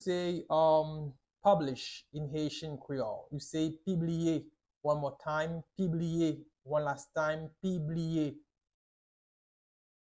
Pronunciation:
22.How-to-say-Publish-in-Haitian-Creole-–-Pibliye-with-pronunciation.mp3